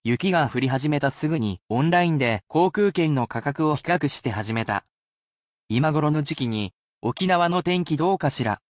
They are at a regular speed, though they are more so for a quick helpful hint and an immersion than to be repeated.
[casual speech]